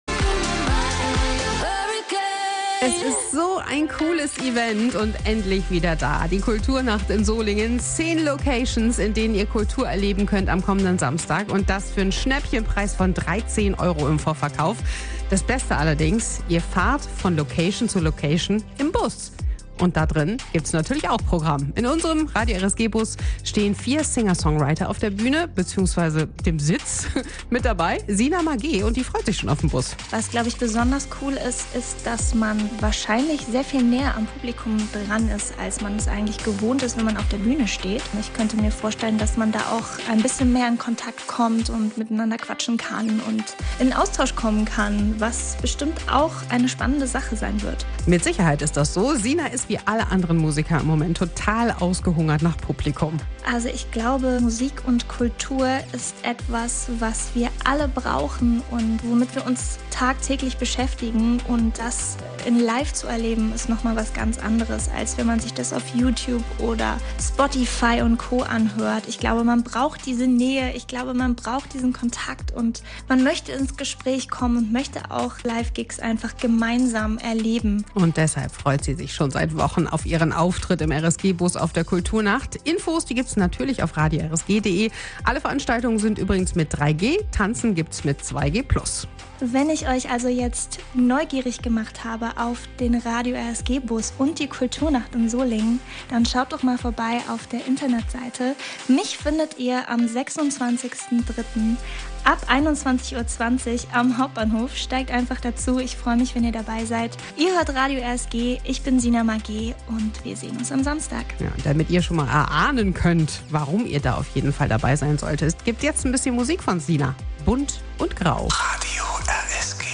Ihre Kompositionen liefern Beats und Rhythmen, in denen sich Soul, Pop und Anleihen aus dem Hip Hop begegnen, garniert mit eingängigen Textzeilen. Dabei präsentiert sie einen Mix aus coolen Club Vibes und relaxter Unplugged-Atmosphäre. Getragen wird alles von ihrer mitreißenden Stimme, die kraftvollen Soul transportiert, aber auch mit leisen, glasklaren Nuancen überzeugt. https
Kultur Nacht Solingen 2022